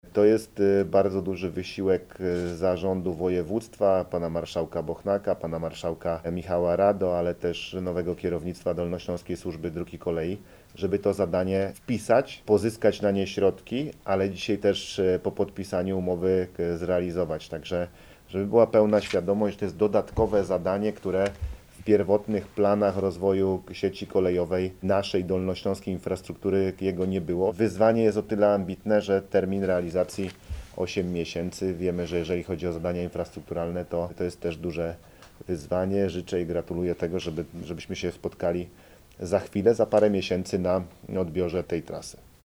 Termin realizacji jest ambitny, życzę wszystkim stronom, abyśmy się spotkali w przyszłym roku na odbiorze tej inwestycji – mówi marszałek Paweł Gancarz.